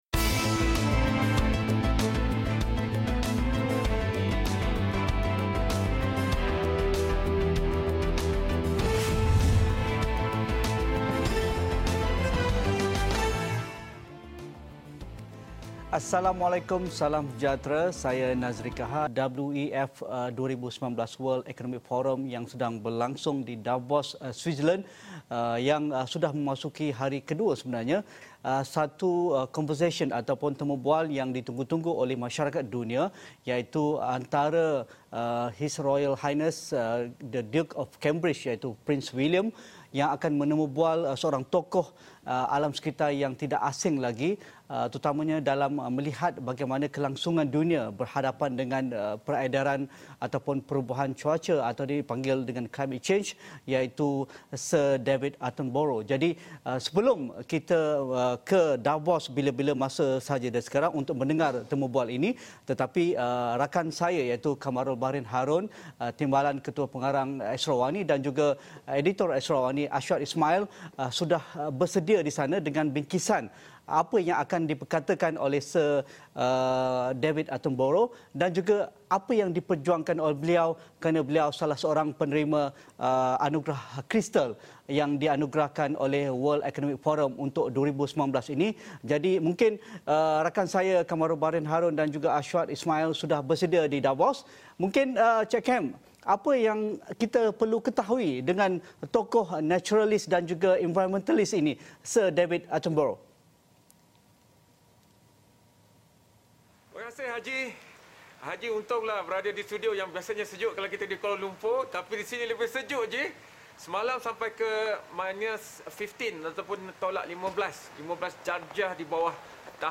WEF 2019 : A Conversation With Sir David Attenborough & HRH The Duke Of Cambridge
A Conversation With Sir David Attenborough & HRH The Duke Of Cambridge.